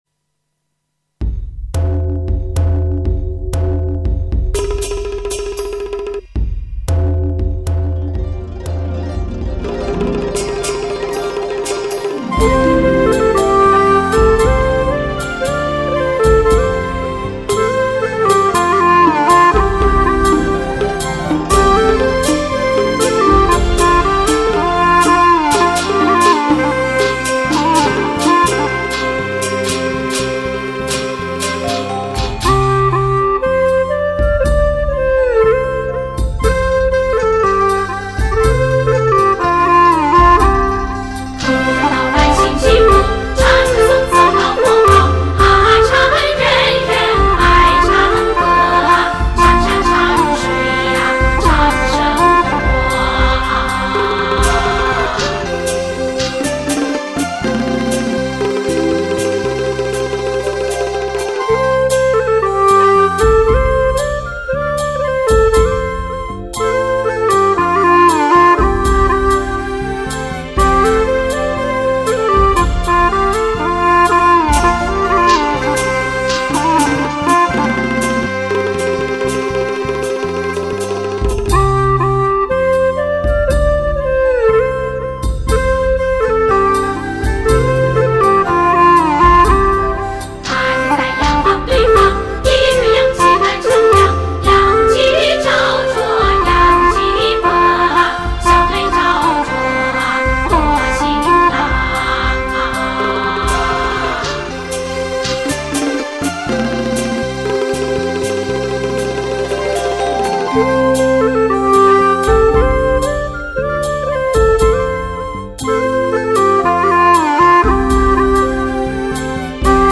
作曲 : 民歌
调式 : D 曲类 : 民族